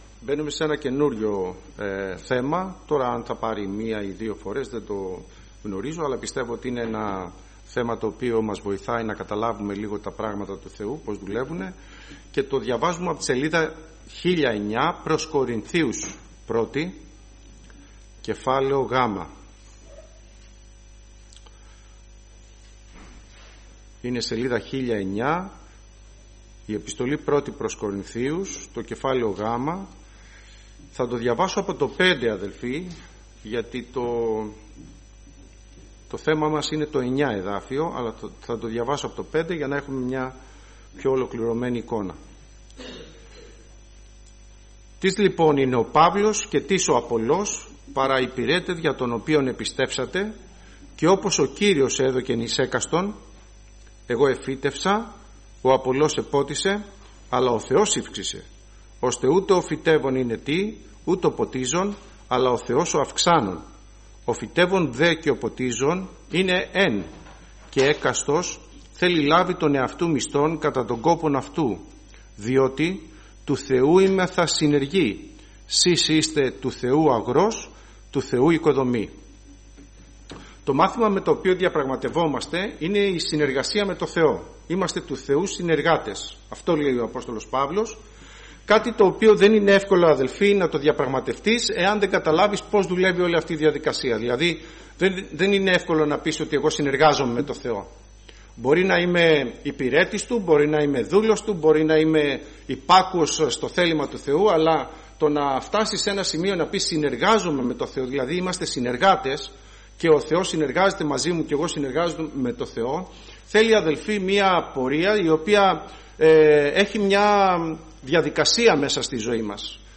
Κηρύγματα - Ελευθέρα Αποστολική Εκκλησία Πεντηκοστής Βούλας